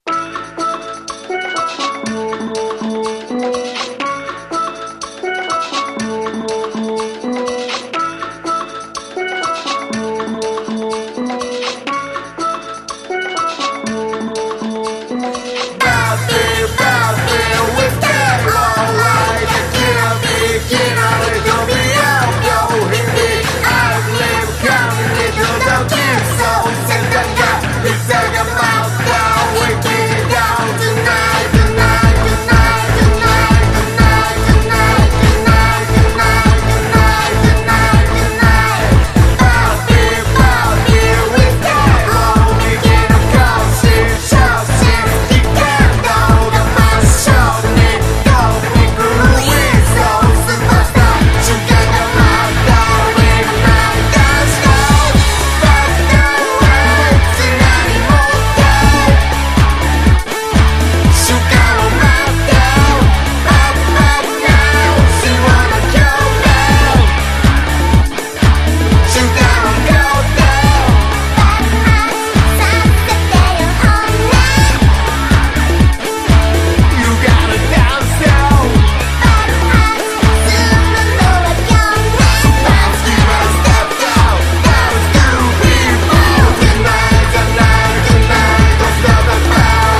驚きの4つ打ちパーティー・チューン